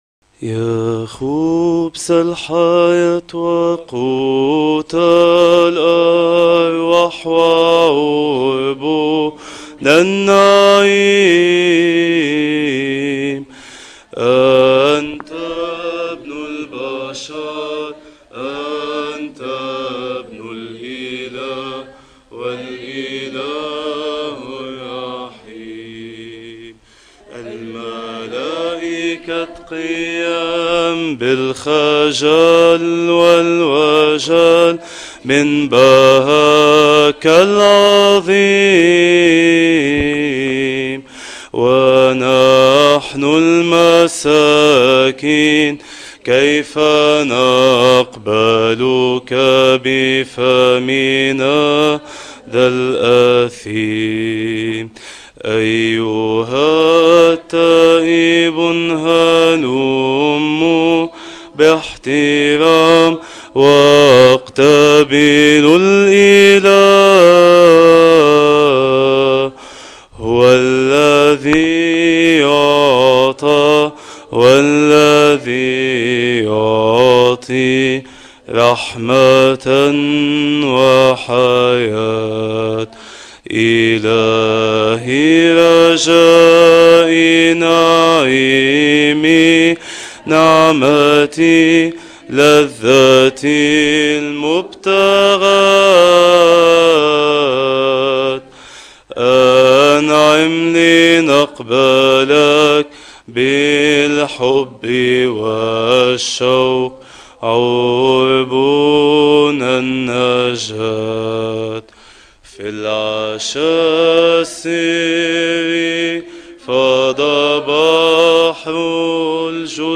من ترانيم القداس الإلهي